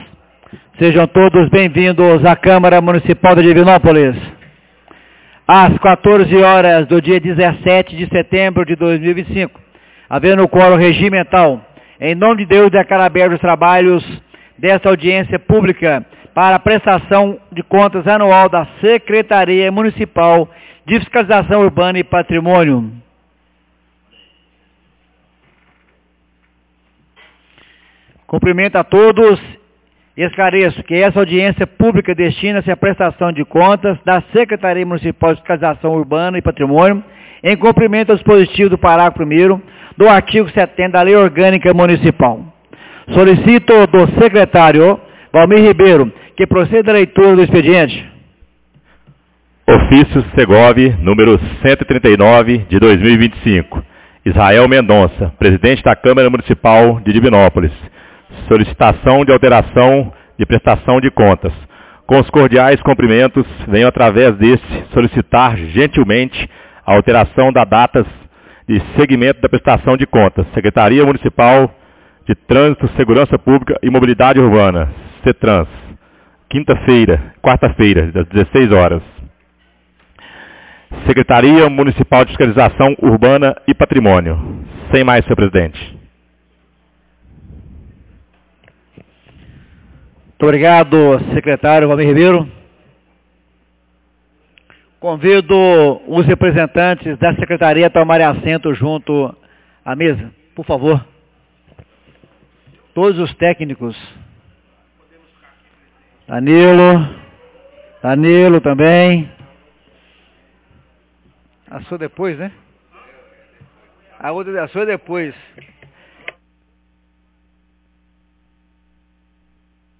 Audiencia publica Prestação de contas da Sec Mun de Fiscalização Urbana e Patrimonio 17 de setembro de 2025